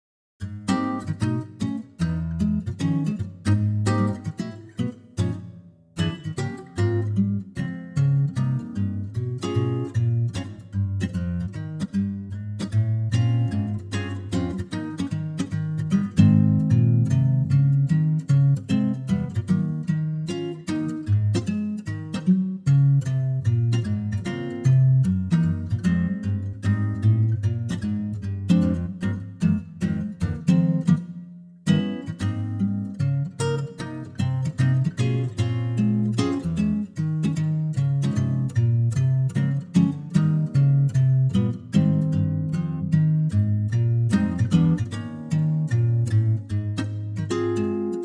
key - Ab - vocal range - C to C
-Unique Backing Track Downloads